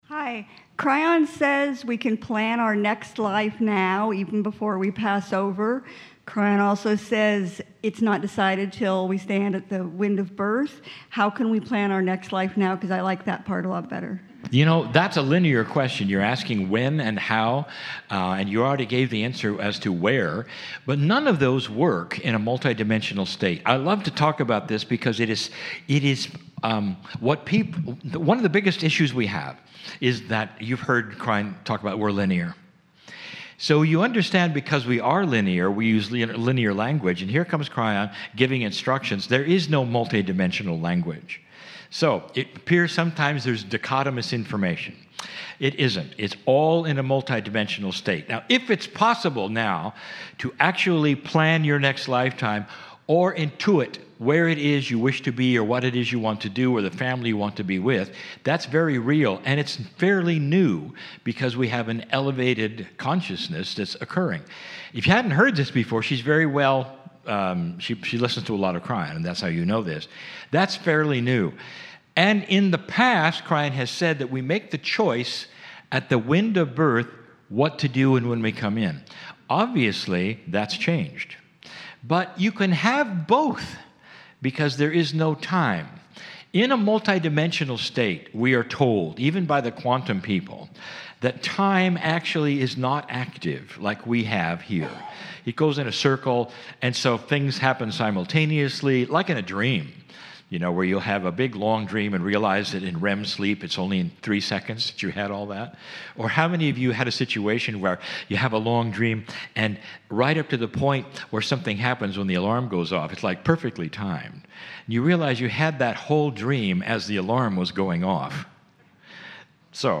SAC-20-Q&A.mp3